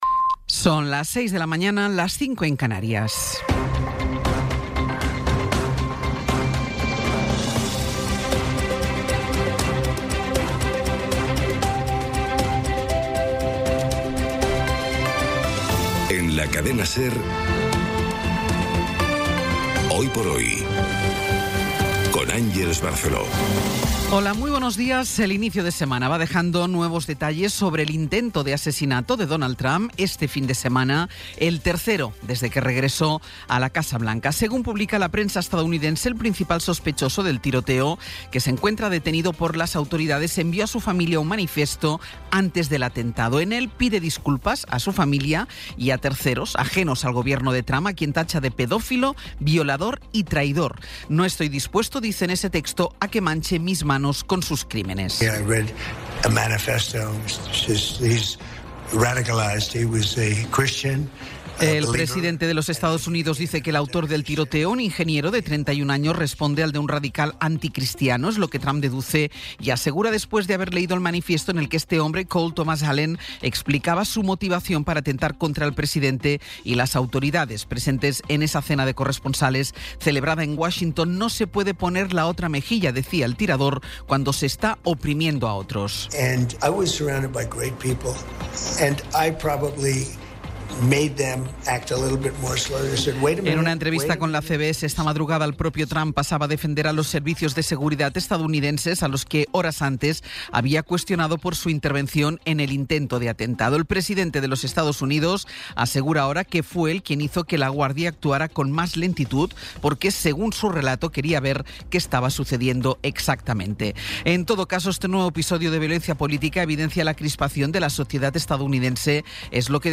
Resumen informativo con las noticias más destacadas del 27 de abril de 2026 a las seis de la mañana.